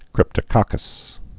(krĭptə-kŏkəs)